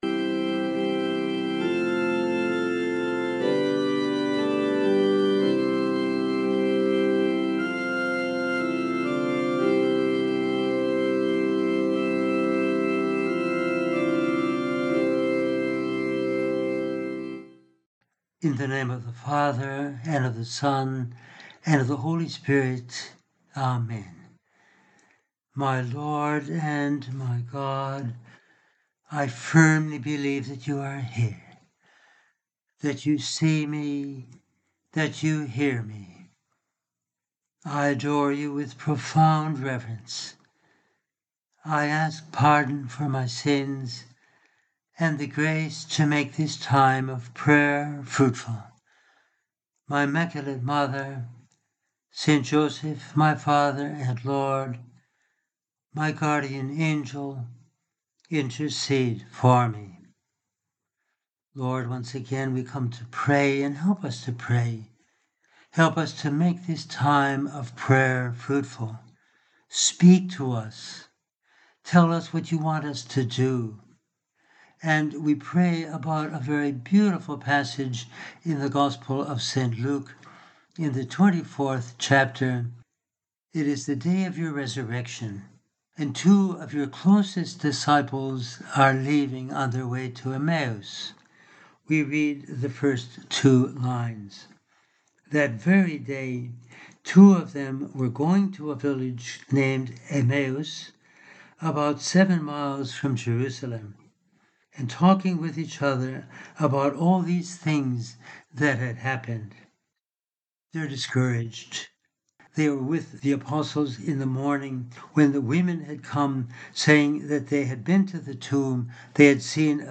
In this meditation we consider how: